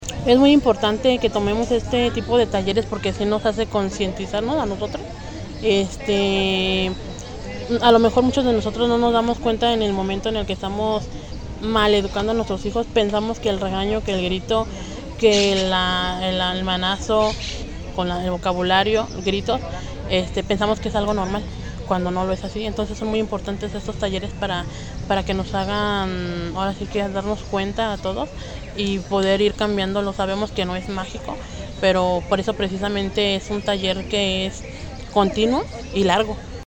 participante